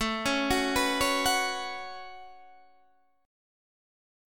A6add9 chord